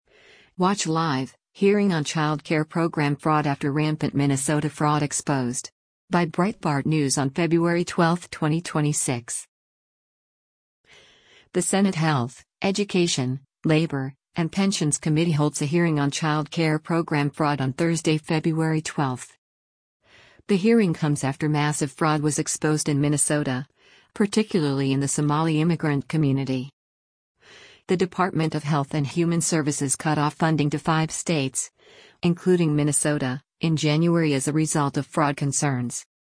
The Senate Health, Education, Labor, and Pensions Committee holds a hearing on child care program fraud on Thursday, February 12.